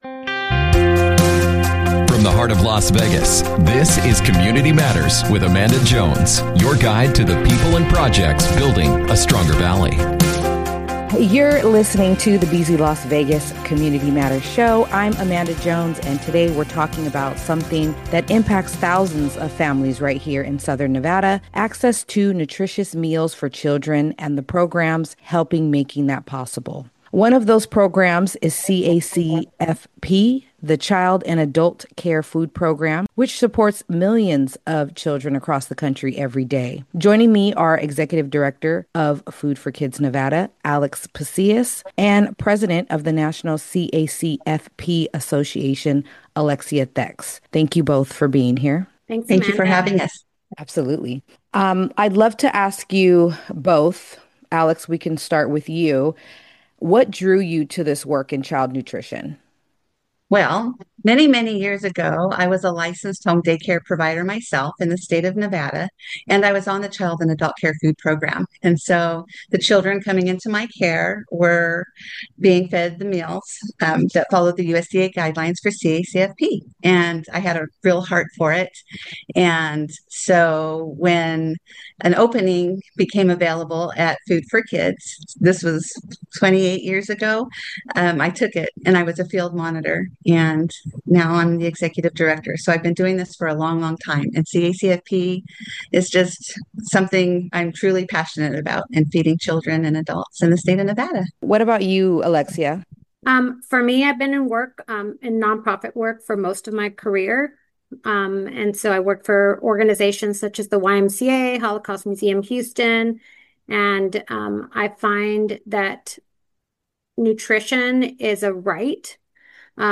NCA leaders brought the CACFP story to Las Vegas radio ahead of the 40th annual National Child Nutrition Conference.